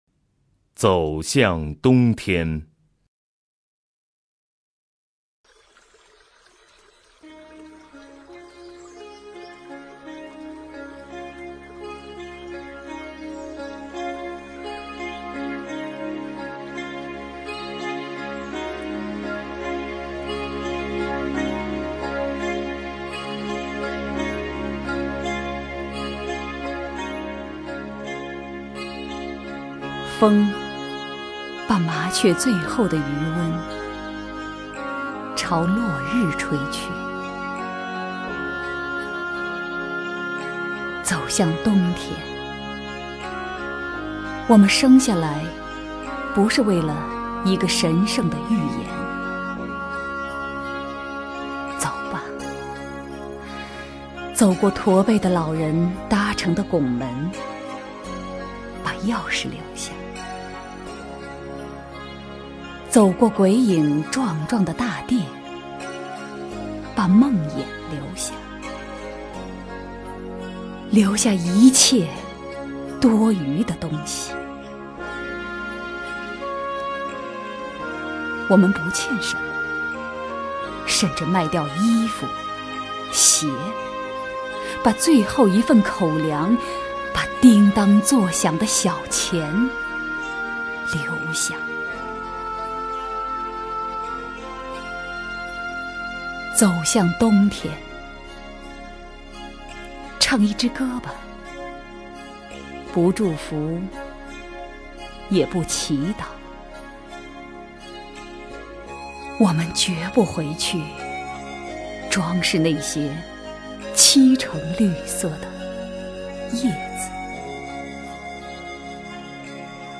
首页 视听 名家朗诵欣赏 狄菲菲
狄菲菲朗诵：《走向冬天》(北岛)　/ 北岛
ZouXiangDongTian_BeiDao(DiFeiFei).mp3